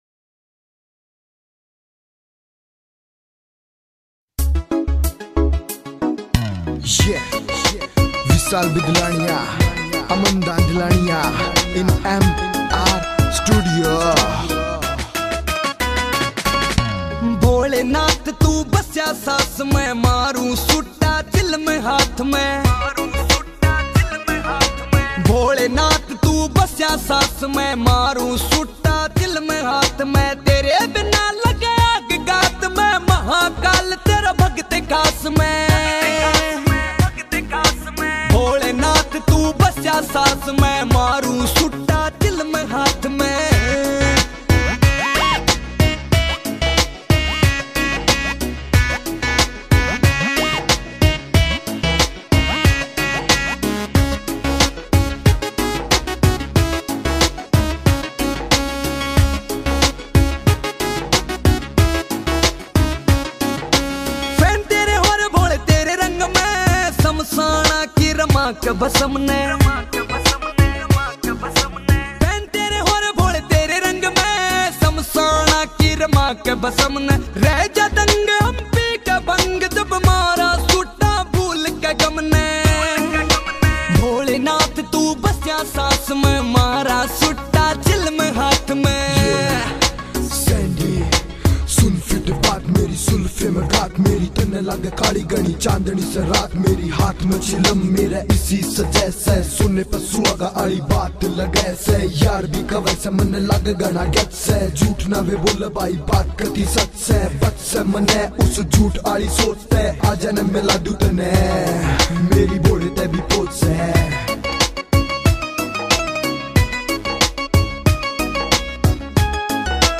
» Bhakti Songs